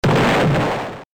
file) 18 KB this is screech explode sound april fools 2024 retro mode survive the drakobloxxers!!!!!!!! 1